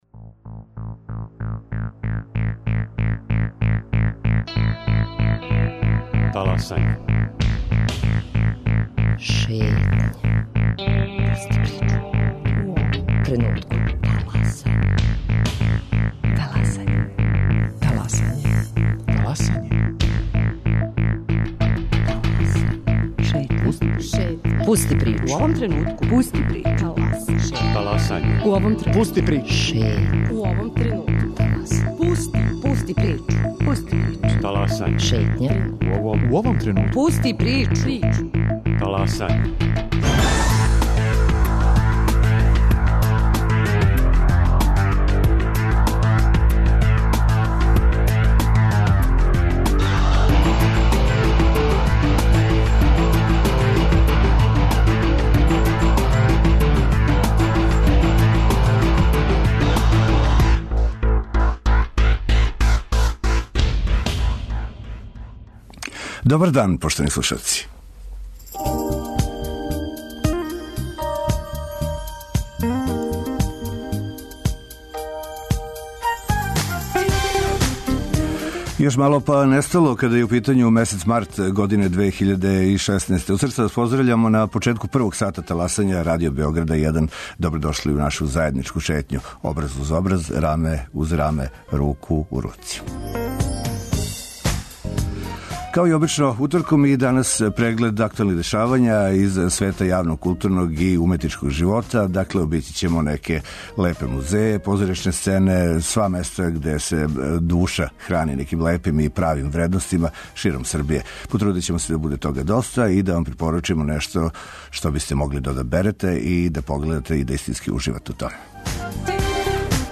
O свим oвим дoгaђajимa гoвoрe aутoри, кустoси, писци, глумци, oни кojи из дaнa у дaн дoпринoсe рaзнoврснoj културнoj пoнуди ширoм Србиje.